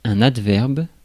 Ääntäminen
Ääntäminen France: IPA: /ad.vɛʁb/ Haettu sana löytyi näillä lähdekielillä: ranska Käännös Konteksti Ääninäyte Substantiivit 1. adverb kielioppi US Suku: m .